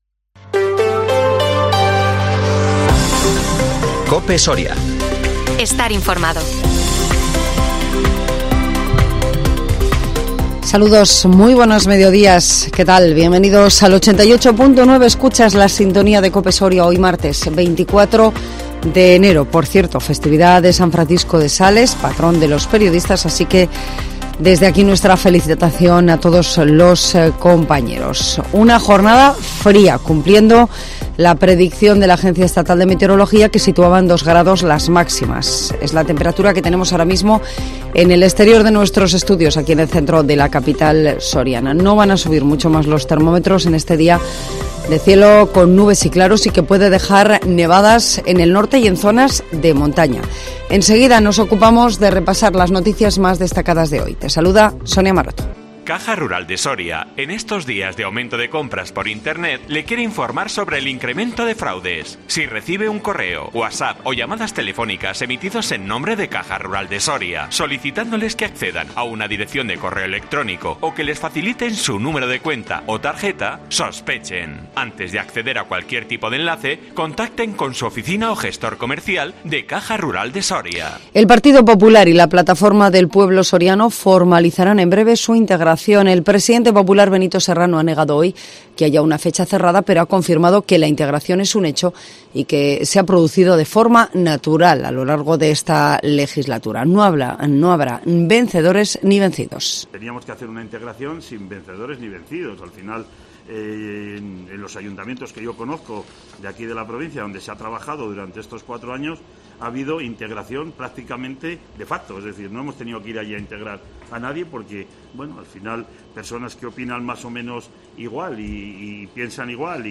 INFORMATIVO MEDIODÍA COPE SORIA 24 ENERO 2023